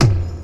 • Old School Short Reggae Tom Drum Single Hit B Key 11.wav
Royality free tom tuned to the B note. Loudest frequency: 1075Hz
old-school-short-reggae-tom-drum-single-hit-b-key-11-m2R.wav